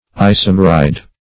isomeride - definition of isomeride - synonyms, pronunciation, spelling from Free Dictionary Search Result for " isomeride" : The Collaborative International Dictionary of English v.0.48: Isomeride \I*som"er*ide\, n. (Chem.)